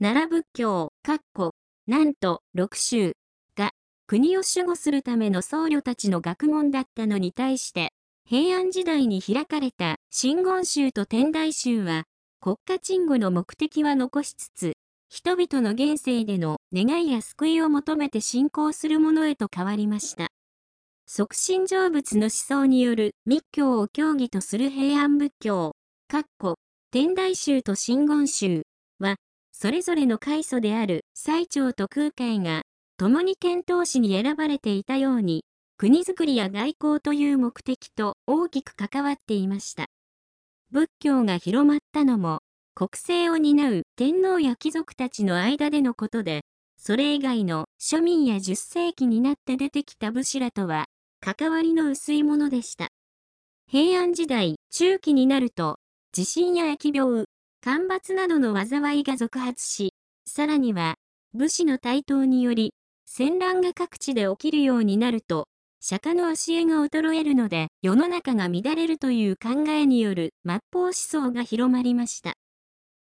読み上げ音声